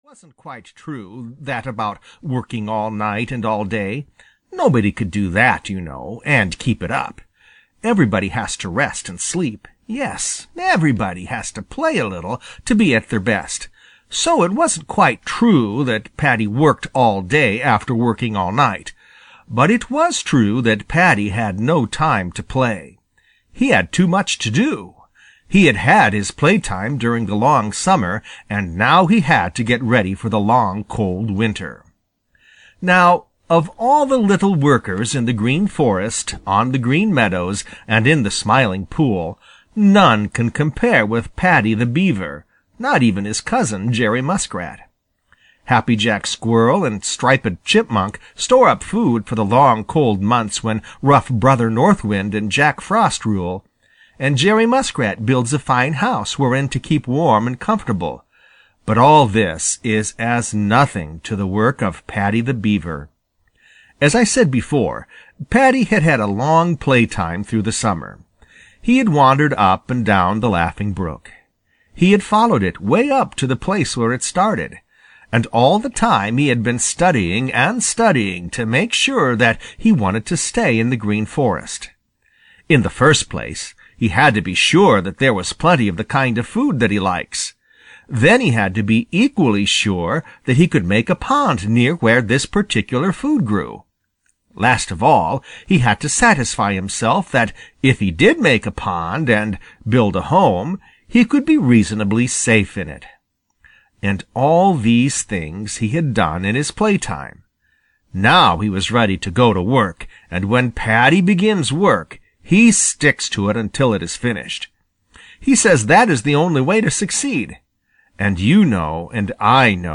The Adventures of Paddy the Beaver (EN) audiokniha
Ukázka z knihy